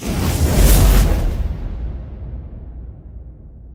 feed_fire.ogg